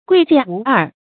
貴賤無二 注音： ㄍㄨㄟˋ ㄐㄧㄢˋ ㄨˊ ㄦˋ 讀音讀法： 意思解釋： 對高貴和卑賤的人態度一樣。